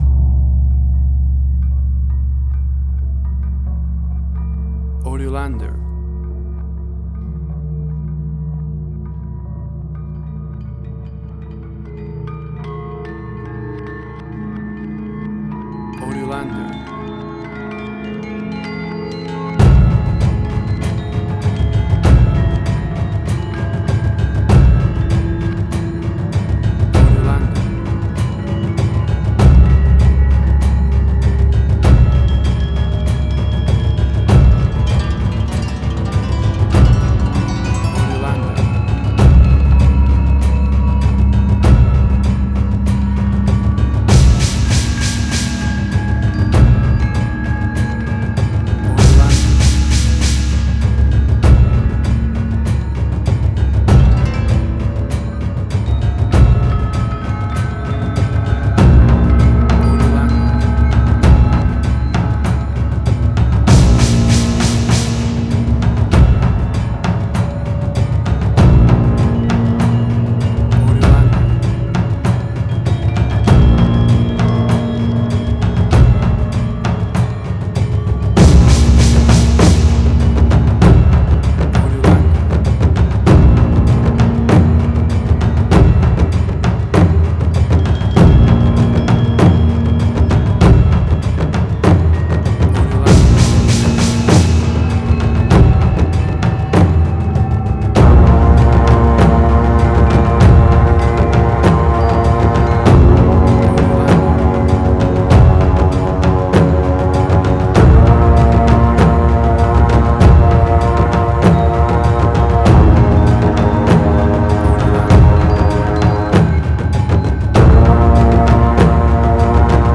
Chinese Action.
Tempo (BPM): 98